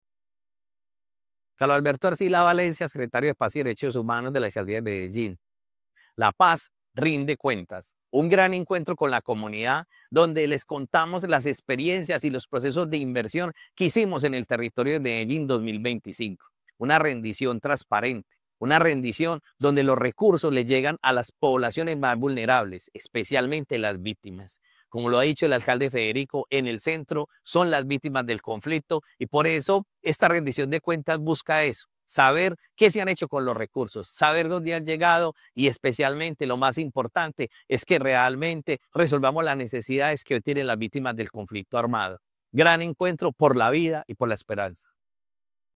Declaraciones del secretario de Paz y Derechos Humanos, Carlos Alberto Arcila Con diversas estrategias de convivencia, participación y reconciliación, la Alcaldía de Medellín reafirma su compromiso con la construcción de paz y la defensa de los derechos humanos en la ciudad.
Declaraciones-del-secretario-de-Paz-y-Derechos-Humanos-Carlos-Alberto-Arcila.mp3